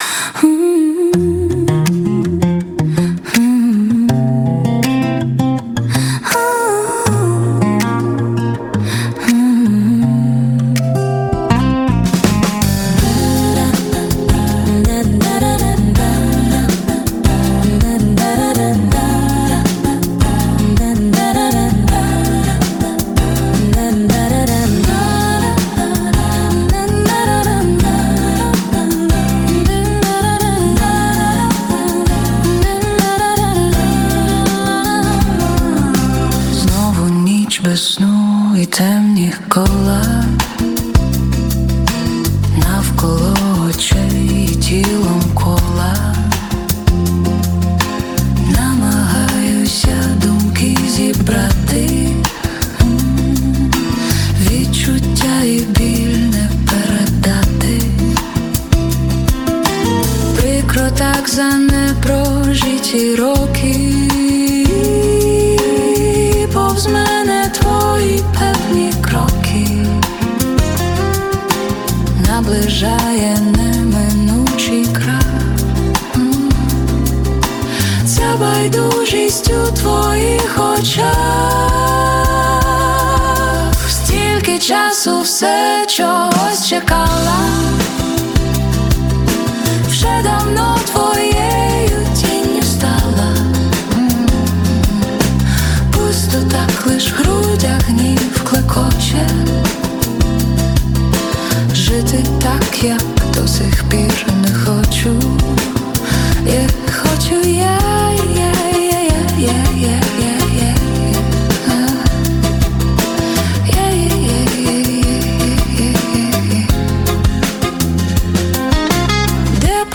Стиль: Фолк-поп